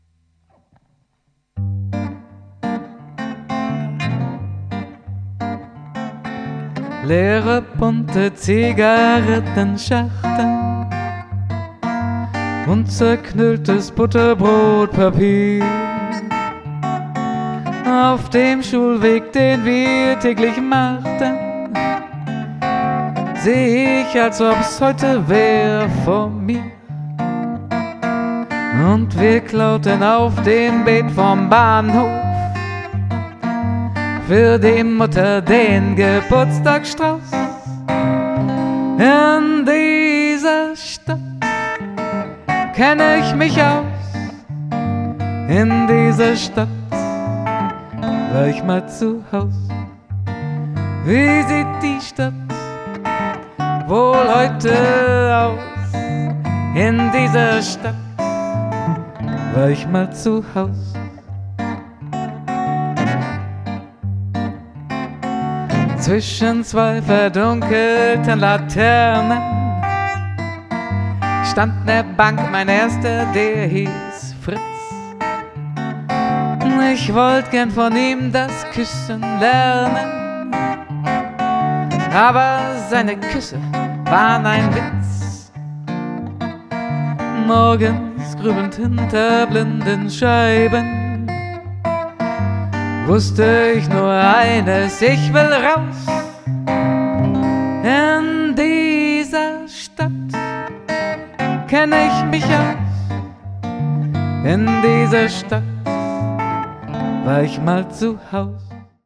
mit ihrer gefühlvollen, tiefen Stimme
mit seiner einfühlsamen Begleitung auf der Gitarre.